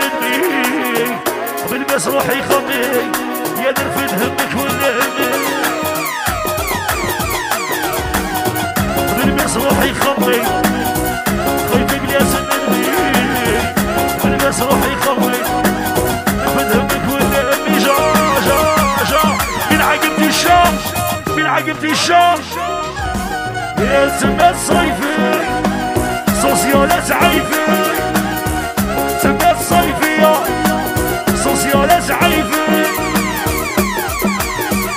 Maghreb Rai
Arabic Pop
Жанр: Поп музыка